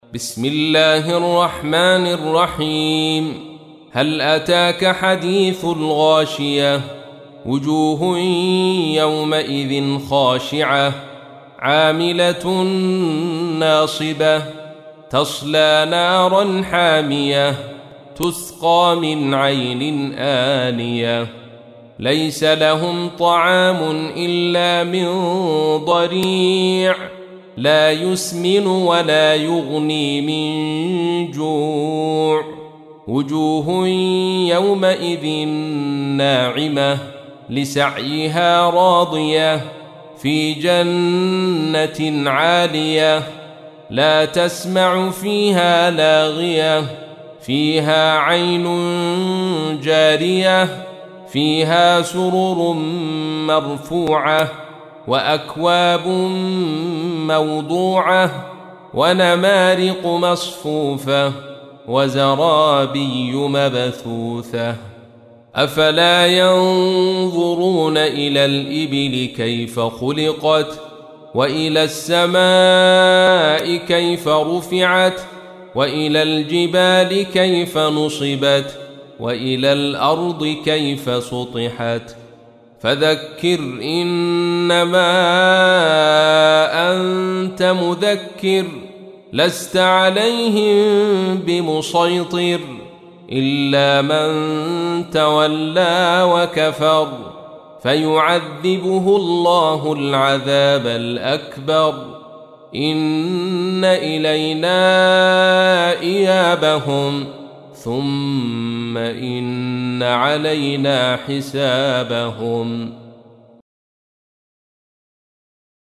تحميل : 88. سورة الغاشية / القارئ عبد الرشيد صوفي / القرآن الكريم / موقع يا حسين